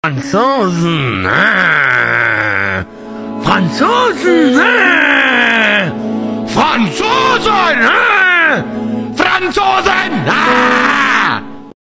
franzosen grrrrr Meme Sound Effect
Category: Meme Soundboard